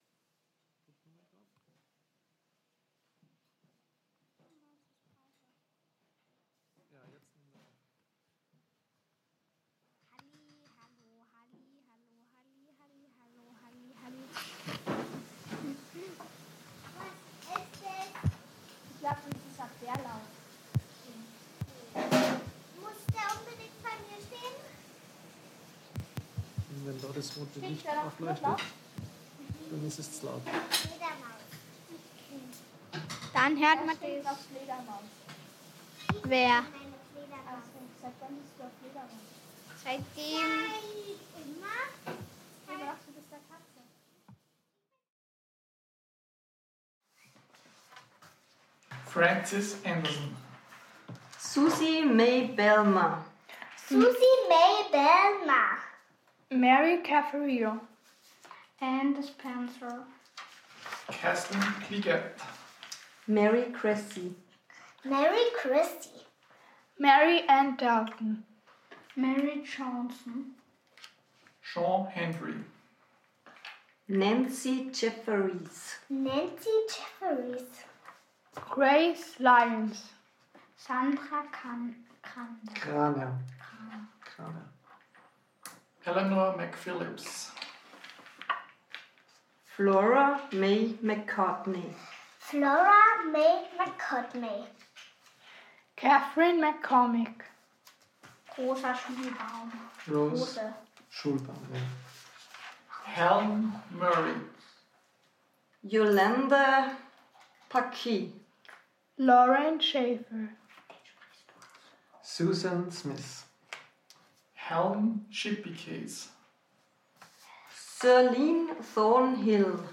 A list of nurses who worked at Hillside between 1959-62. Their names voiced by myself and members of my family in an informal setting.